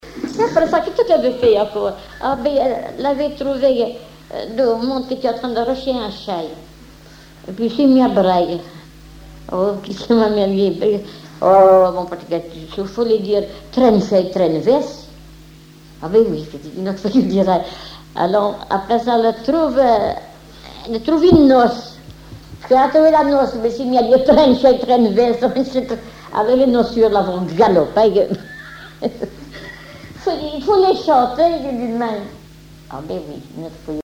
Genre conte
Enquête La Soulère, La Roche-sur-Yon
Catégorie Récit